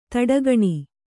♪ taḍagaṇi